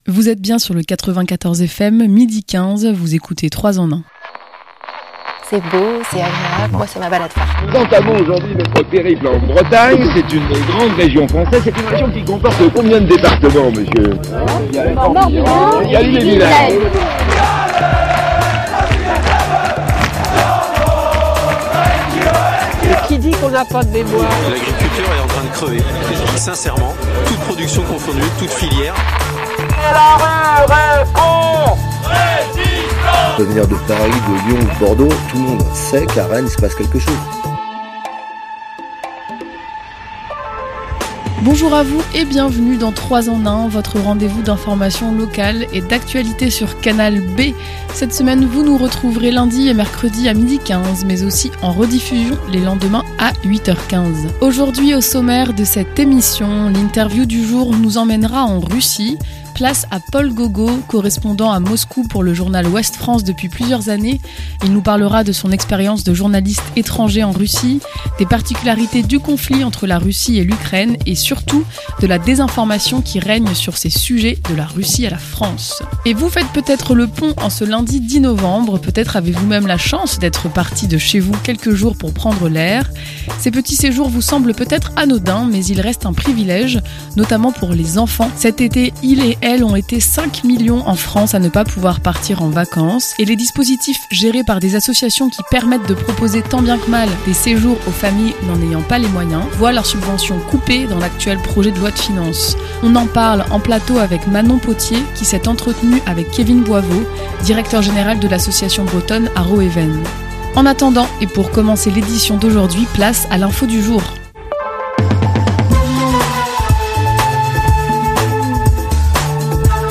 L'interview
La chronique